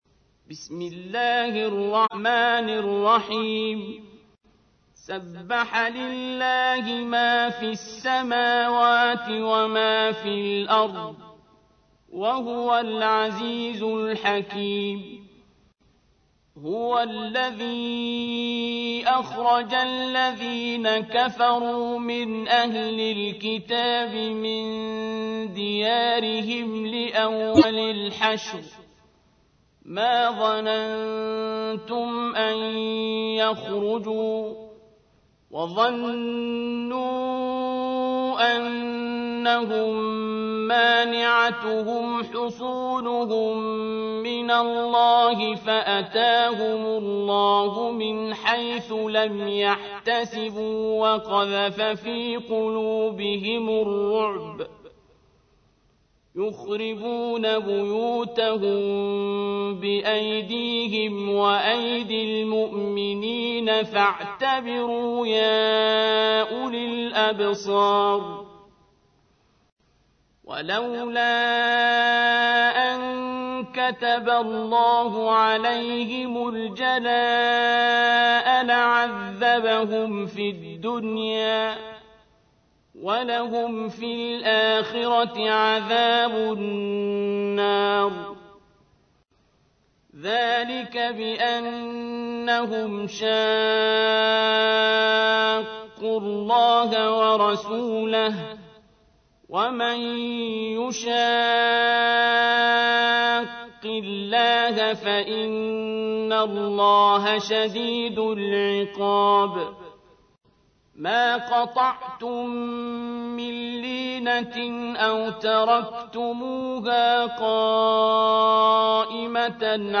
تحميل : 59. سورة الحشر / القارئ عبد الباسط عبد الصمد / القرآن الكريم / موقع يا حسين